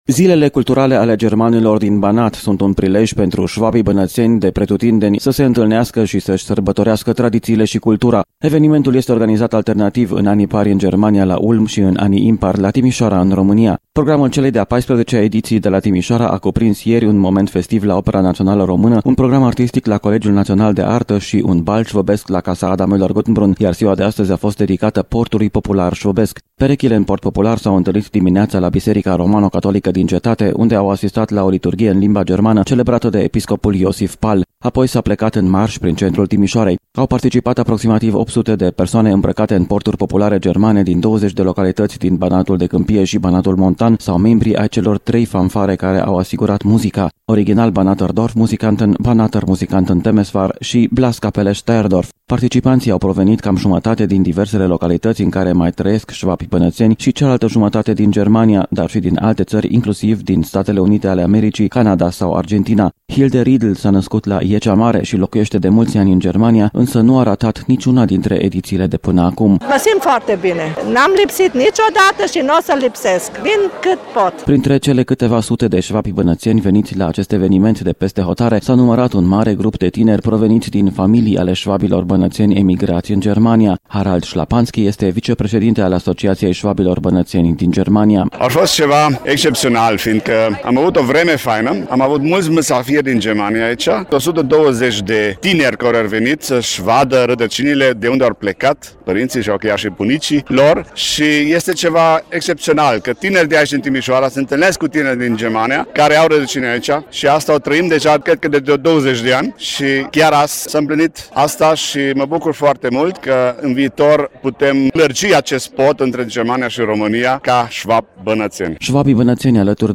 Zilele Culturale ale Germanilor din Banat s-au încheiat, astăzi la prânz, cu o grandioasă paradă a portului popular şvăbesc prin centrul Timişoarei. 356 de perechi au mărşăluit în ritmurile muzicii de alămuri asigurate de trei fanfare.